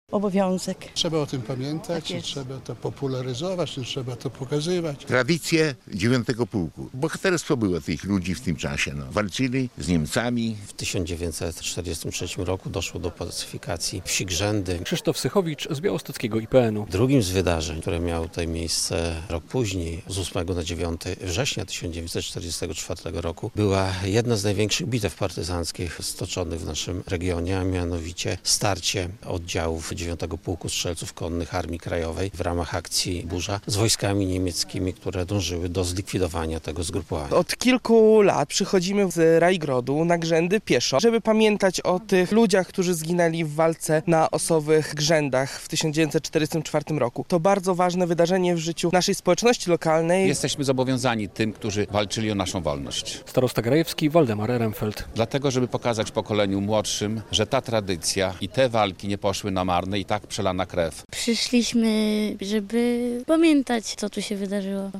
80. rocznica bitwy koło miejscowości Osowe Grzędy - relacja